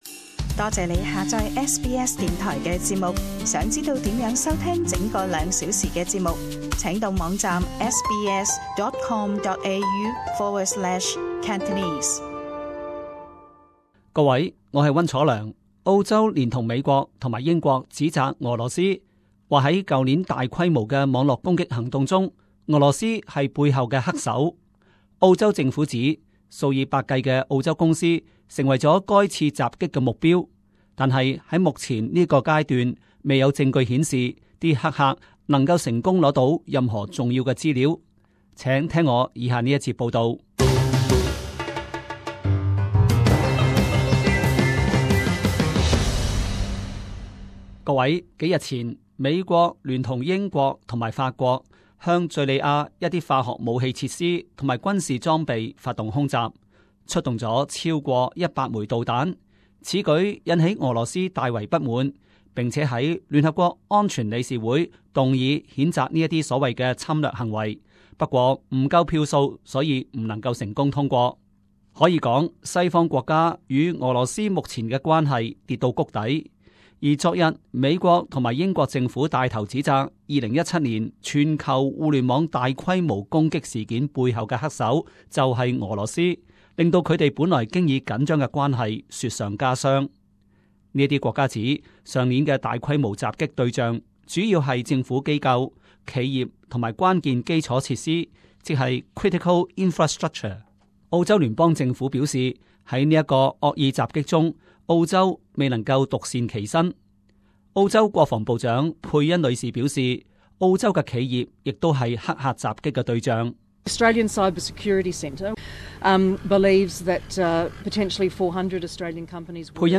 【時事報導】俄羅斯去年曾經向澳洲發動龐大的網絡入侵攻擊